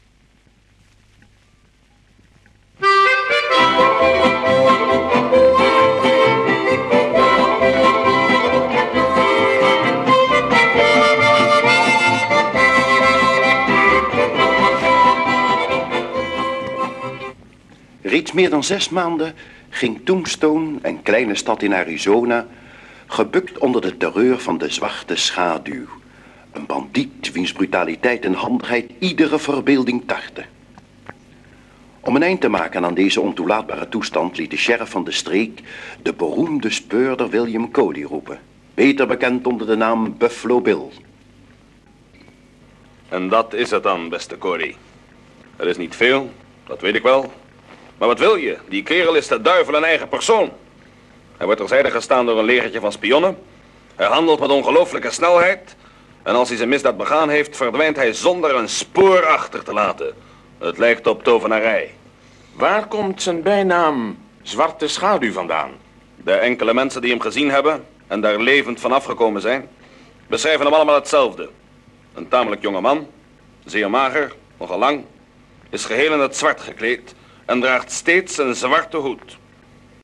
Hoorspel
25 cm LP | 33 toeren